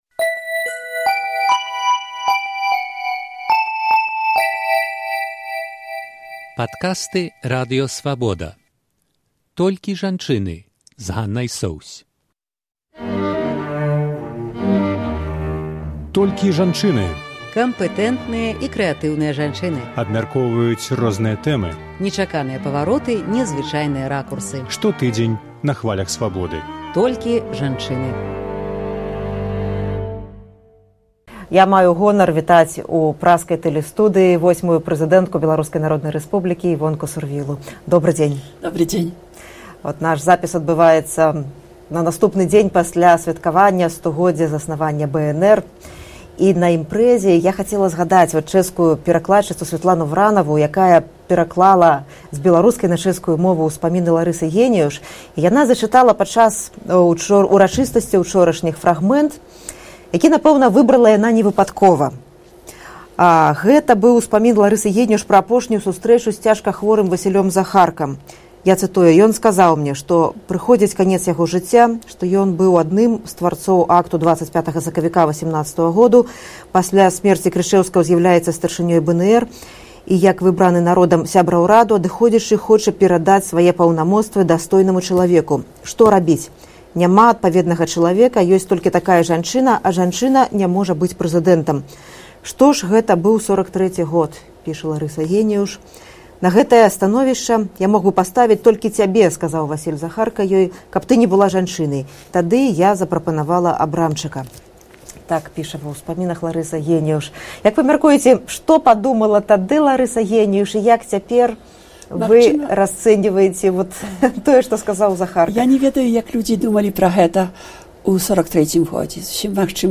Восьмая старшыня Рады БНР Івонка Сурвіла ў вялікім інтэрвію